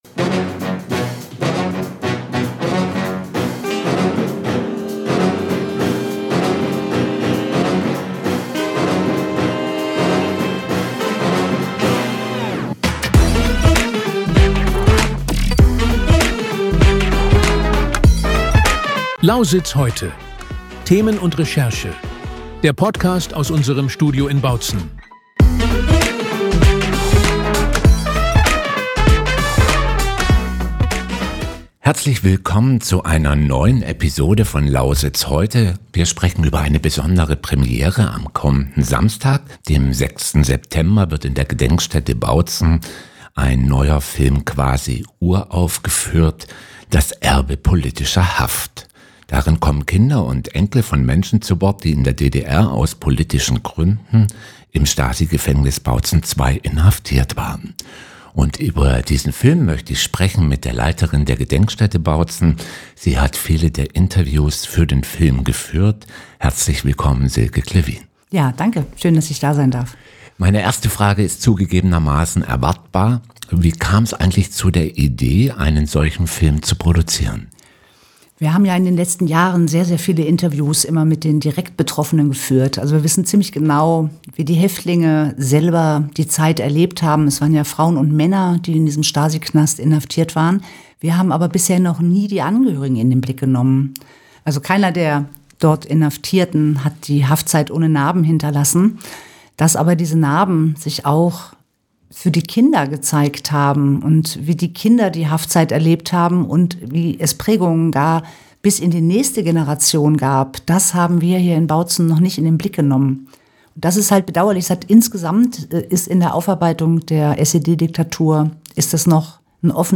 Ein Gespräch über die langen Schatten der Diktatur, die bis in die dritte Generation reichen, und darüber, warum diese Aufarbeitung auch ein Lehrstück für unsere Demokratie sein will.